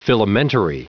Prononciation du mot filamentary en anglais (fichier audio)
Prononciation du mot : filamentary
filamentary.wav